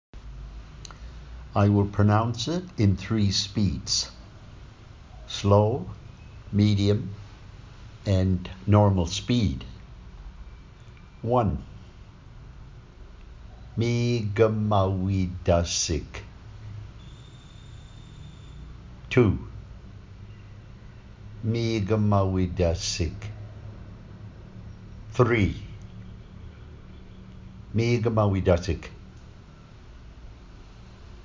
Mi’kmawitasik: Meeg-maw-wee-duh-sig
Mikmawitasik_Pronunciation.mp3